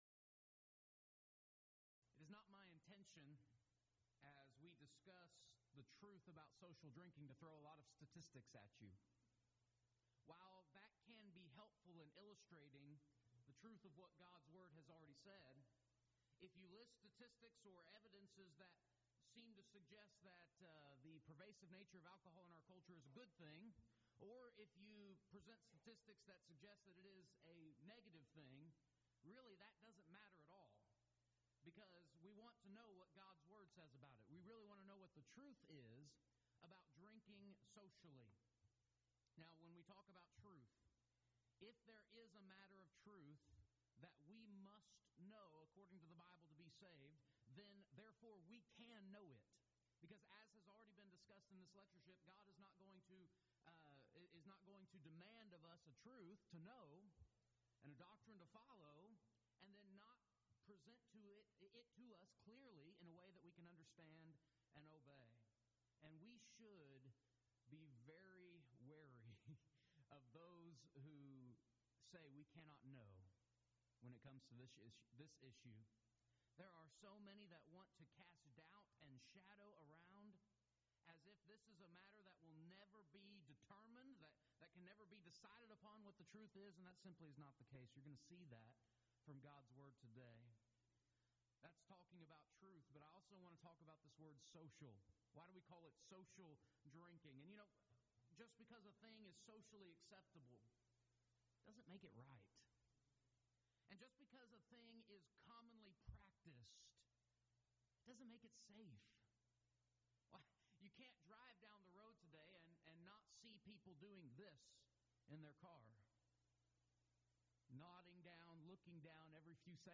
Event: 2nd Annual Colleyville Lectures
lecture